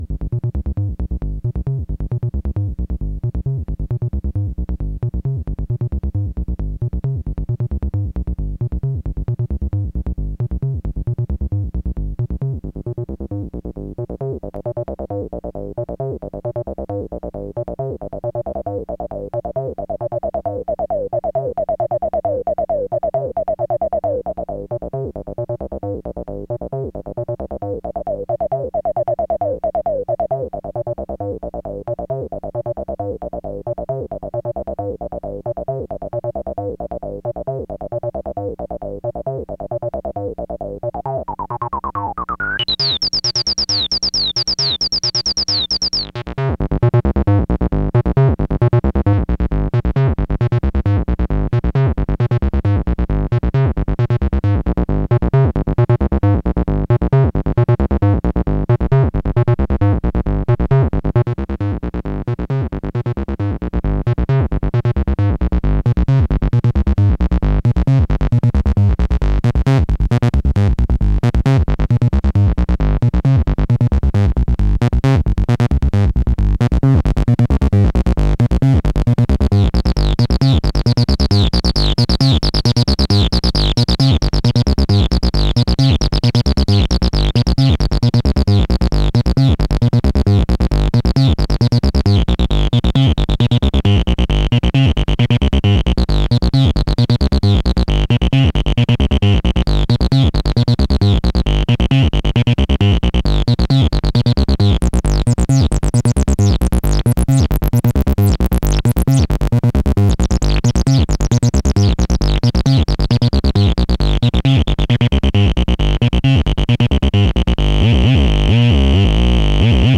(pretty subtle use of mods here: drippyness and rubber bandy forever note slurring demonstrated)
As you can see, many of the effects are subtle, they're still x0xb0x, but twisted